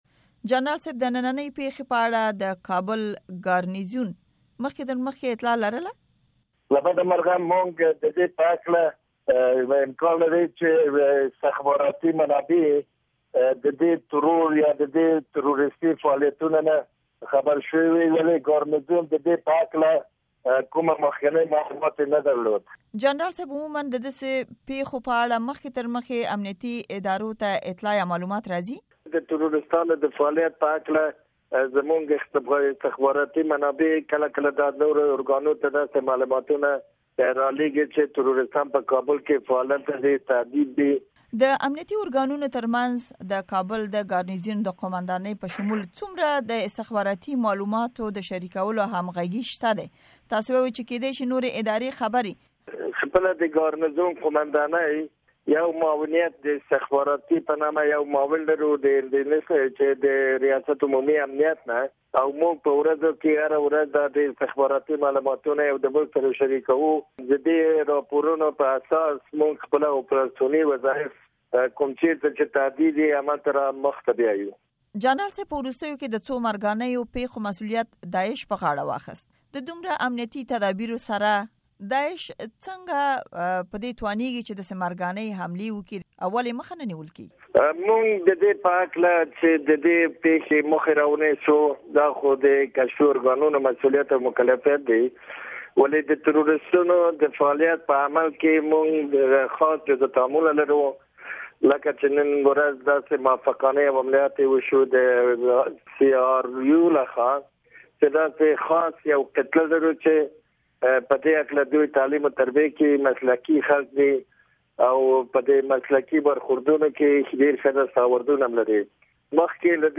امريکا غږ سره د جنرال افضل امان مرکه